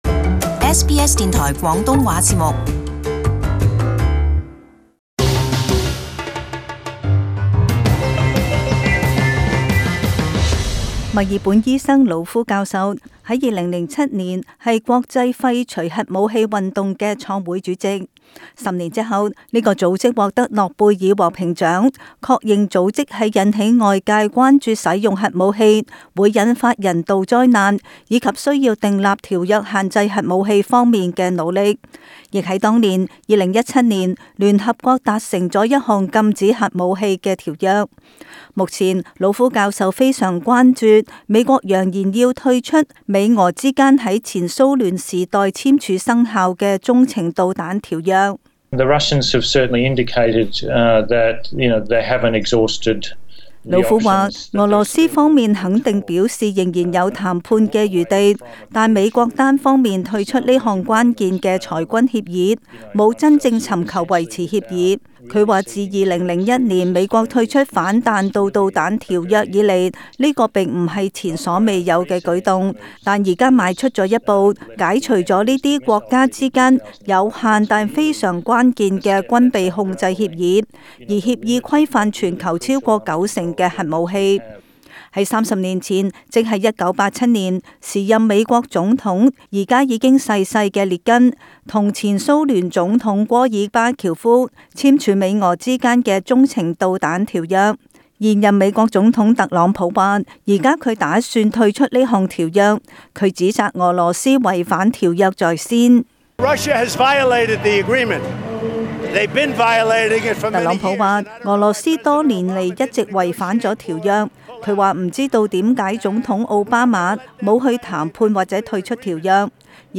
【時事報導】美國退出《中程導彈條約》